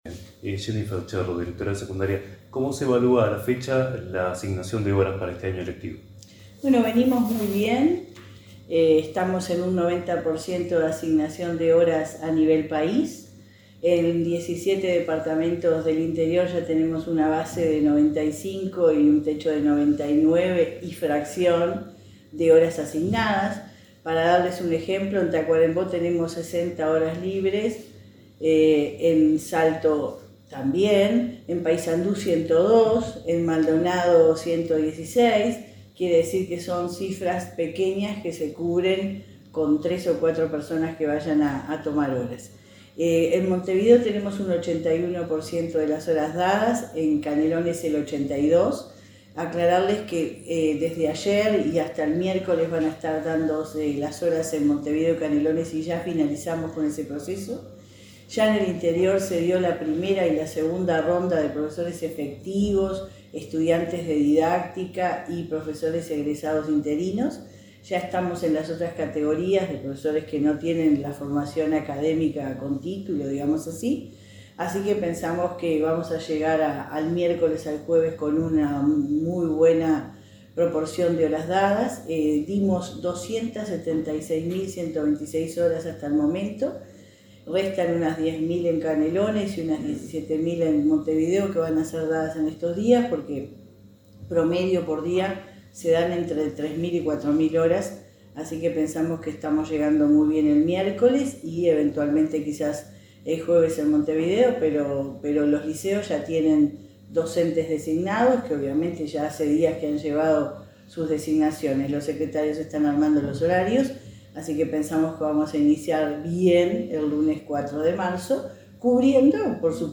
Entrevista a la directora general de Educación Secundaria, Jenifer Cherro
La directora general de Educación Secundaria, Jenifer Cherro, dialogó con Comunicación Presidencial, acerca del comienzo de las clases y la elección